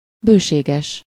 Ääntäminen
Synonyymit grand nombreux riche généreux prodigue fécond Ääntäminen France: IPA: [a.bɔ̃.dɑ̃] Haettu sana löytyi näillä lähdekielillä: ranska Käännös Ääninäyte Adjektiivit 1. bőséges 2. bővelkedő Suku: m .